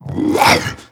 c_agrunt_atk1.wav